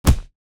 body_hit_small_23.wav